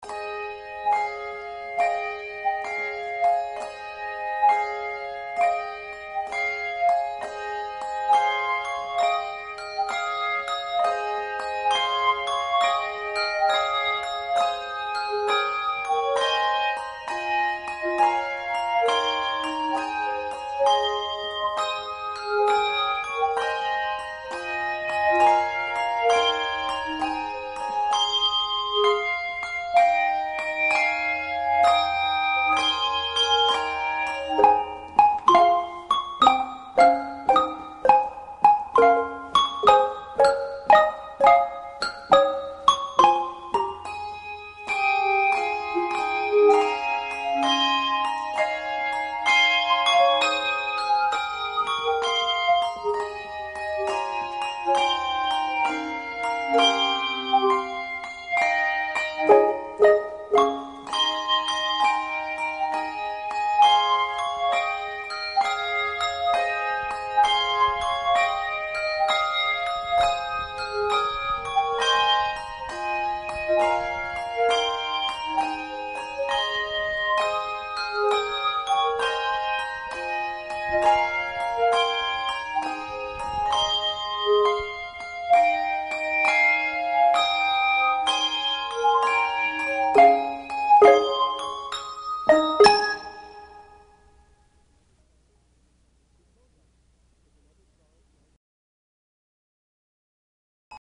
Handbell Quartet
Genre Sacred
No. Octaves 4 Octaves